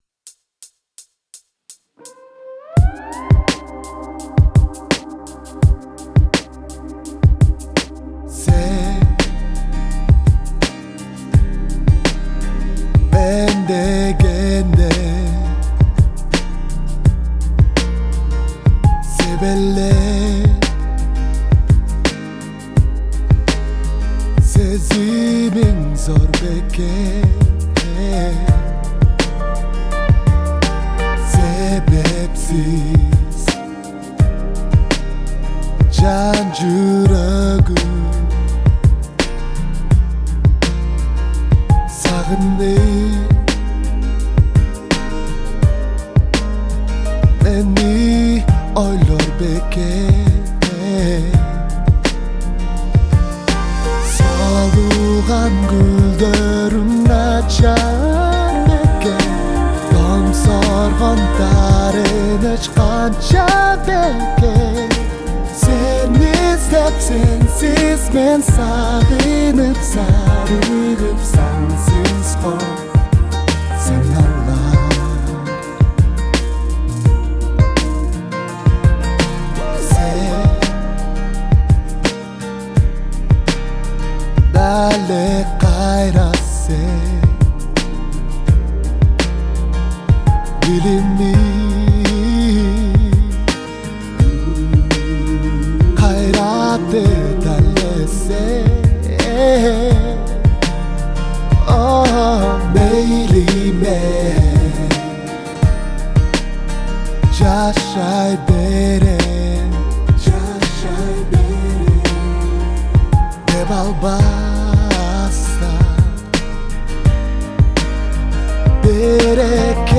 Трек размещён в разделе Киргизская музыка.